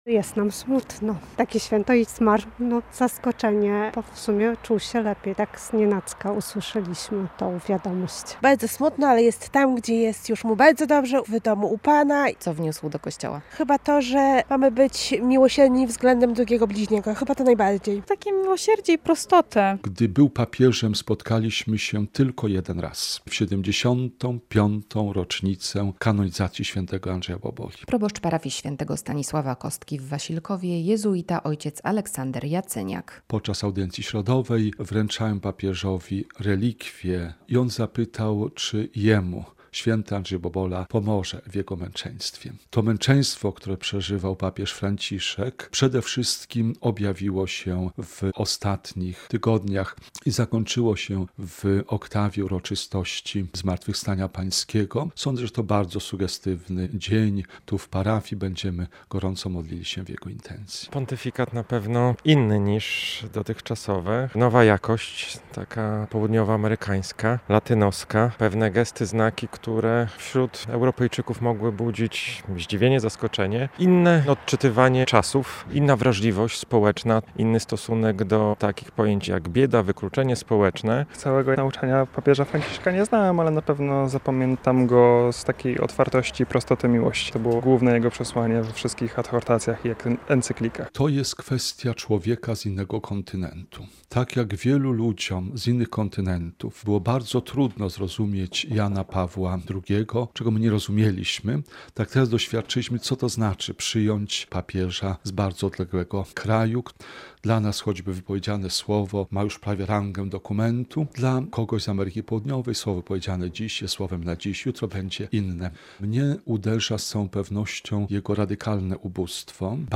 Jak wspominamy papieża Franciszka - relacja